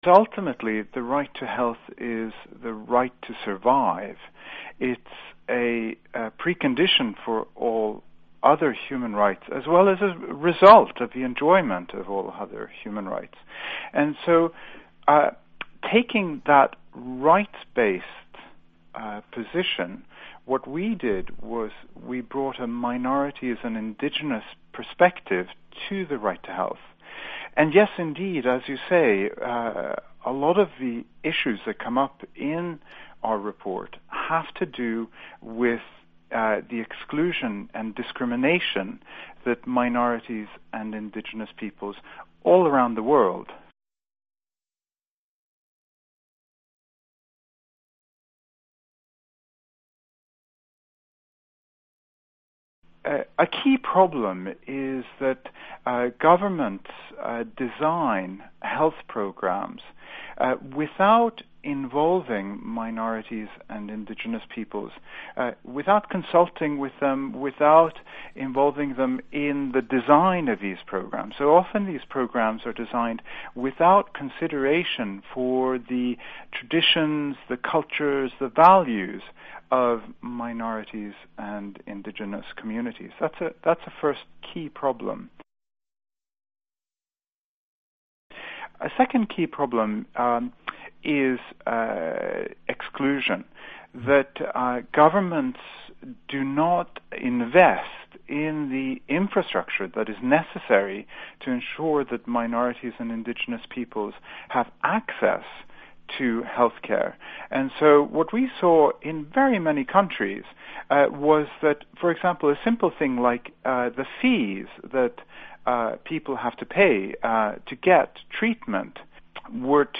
འབྲེལ་ཡོད་མི་སྣ་ཞིག་ལ་གནས་འདྲི་ཞུས་ཏེ